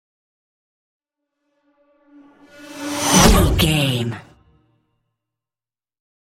Pass by fast speed engine
Sound Effects
futuristic
pass by
car
vehicle